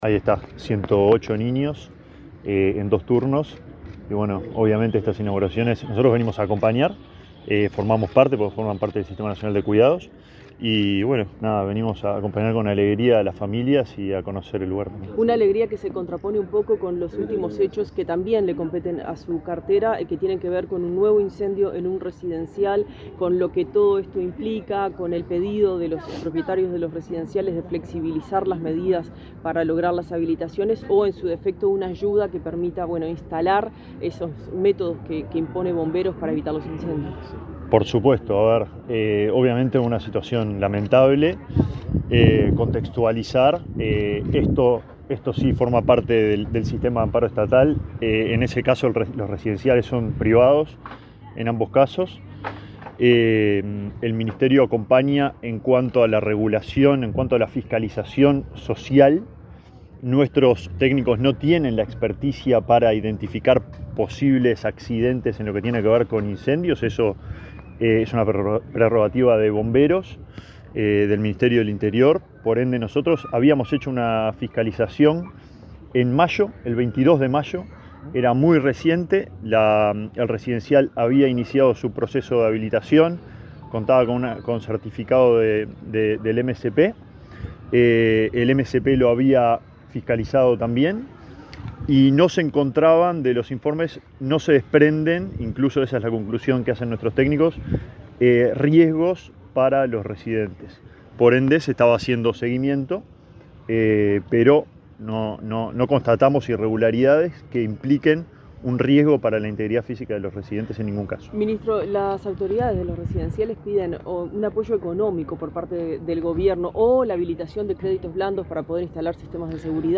El ministro de Desarrollo Social, Alejandro Sciarra, efectuó declaraciones a medios informativos, luego de participar de la inauguración de un centro